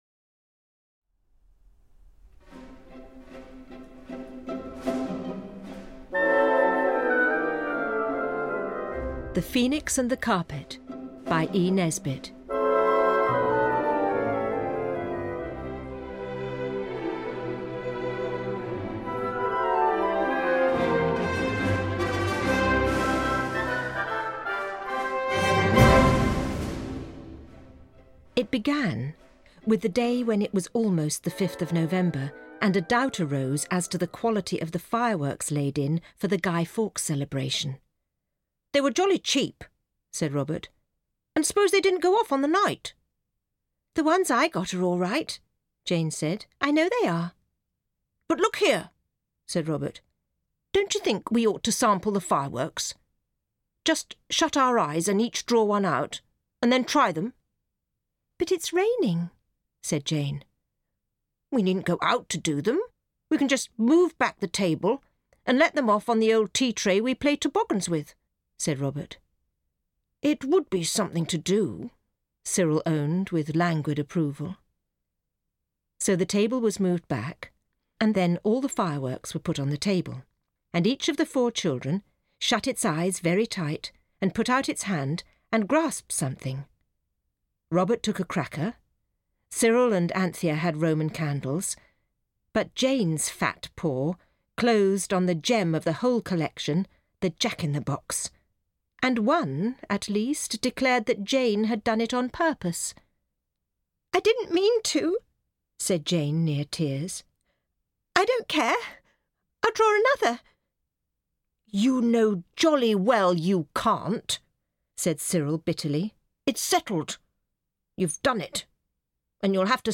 Audio kniha
With delightful music of the period.